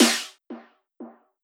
Snare (Shut It Down).wav